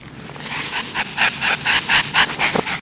bcl_alert2.ogg